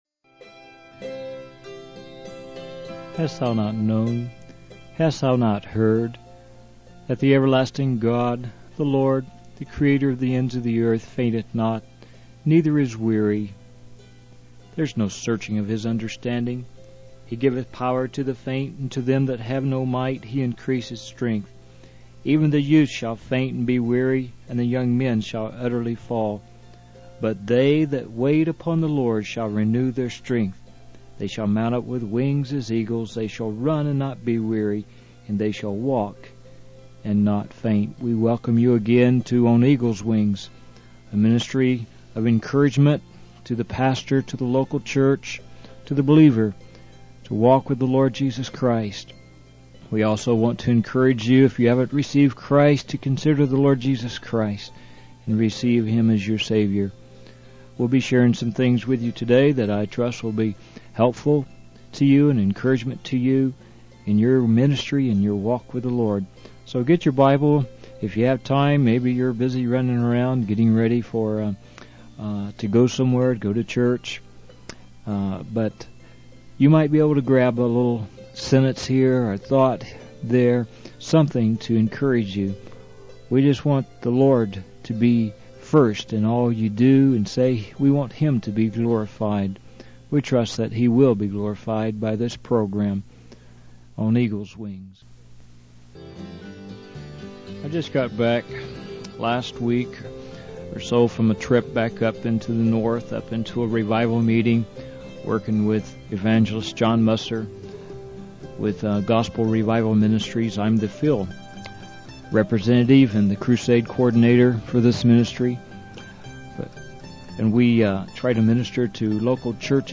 In this sermon, the preacher shares stories and letters from pastors in countries facing economic depression and limited access to religious materials. He highlights the dedication and growth of churches in these areas, where pastors start with just a few people and see remarkable conversions. The preacher emphasizes the need for support and prayers to provide books and Bibles to these believers.